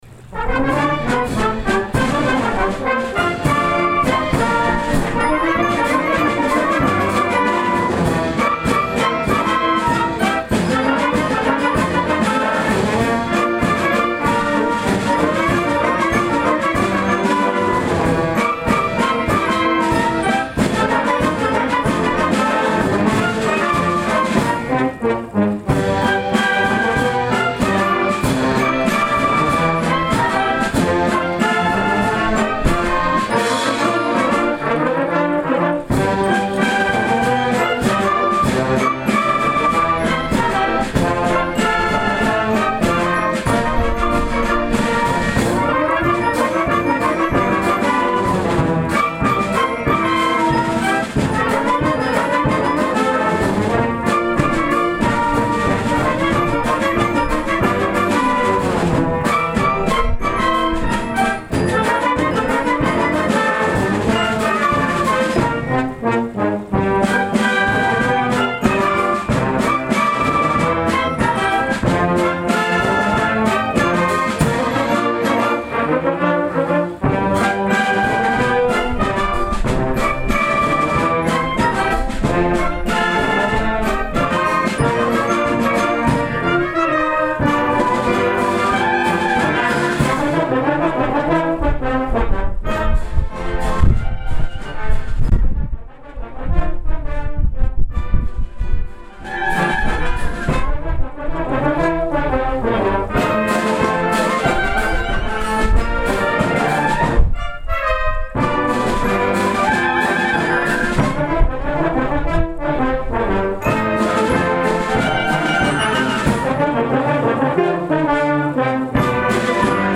The Karl L. King Municipal Band of Fort Dodge, Iowa
June 9, 2019                        This June concert began the 8 week Summer outdoor season.
The windy weather of this evening concert
Also included from 1919 on this concert was Karl King's two-step Kentucky Sunrise.